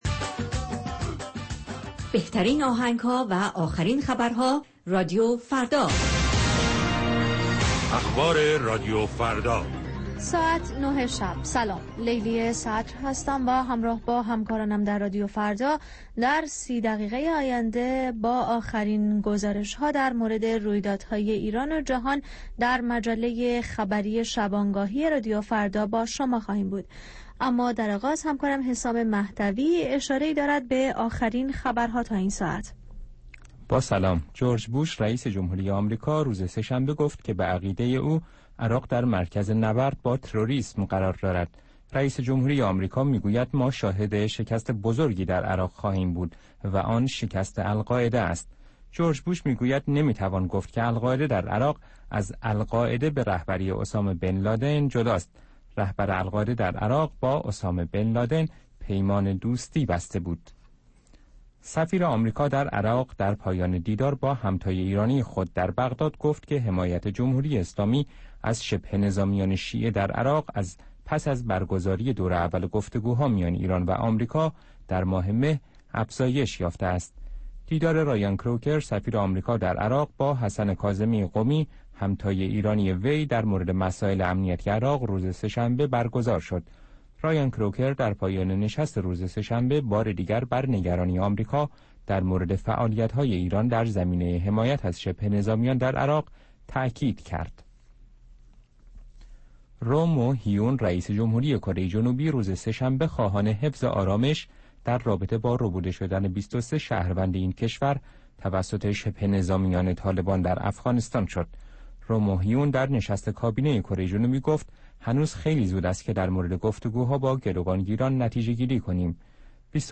نیم ساعت با تازه ترین خبرها، گزارشهای دست اول در باره آخرین تحولات جهان و ایران از گزارشگران رادیوفردا در چهارگوشه جهان، گفتگوهای اختصاصی با چهره های خبرساز و کارشناسان، و مطالب شنیدنی از دنیای سیاست، اقتصاد، فرهنگ، دانش و ورزش.